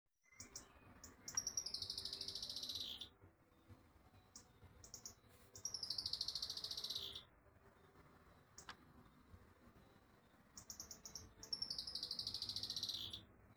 Sicalis flaveola pelzelni
English Name: Saffron Finch
Sex: Male
Life Stage: Adult
Country: Argentina
Location or protected area: Coronel Suárez
Condition: Wild
Certainty: Observed, Recorded vocal